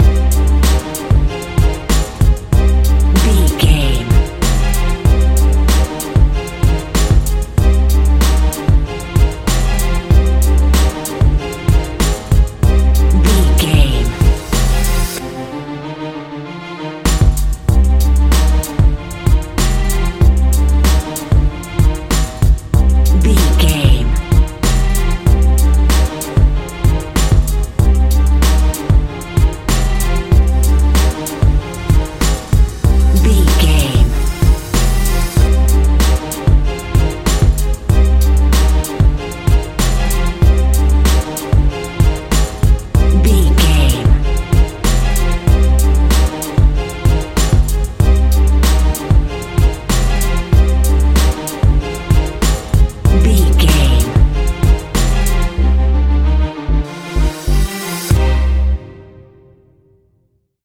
Aeolian/Minor
synthesiser
strings
hip hop
Funk